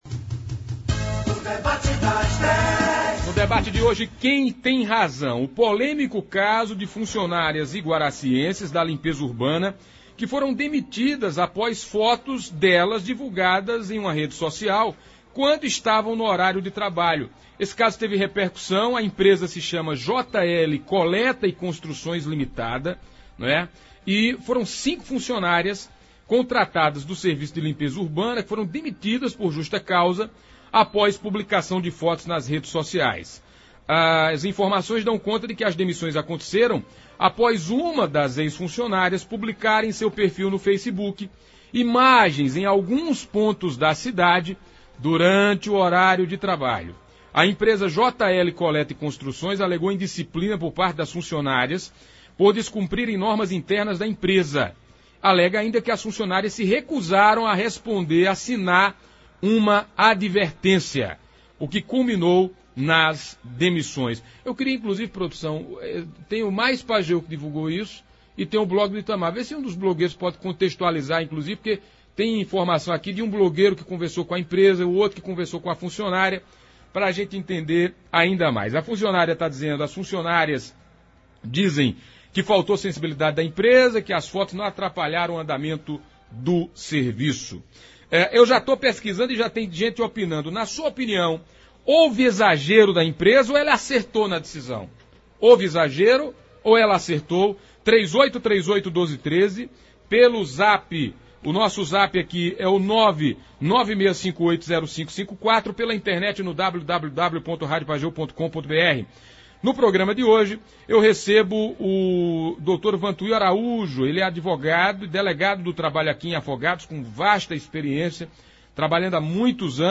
Ouvintes e internautas se manifestaram em sua grande maioria contra a ação da empresa, achando que foi exagero.